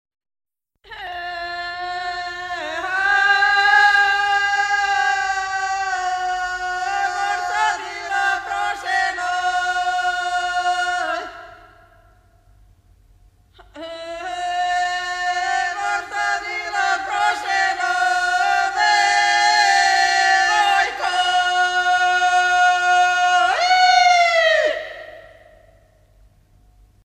circonstance : fiançaille, noce
Pièce musicale éditée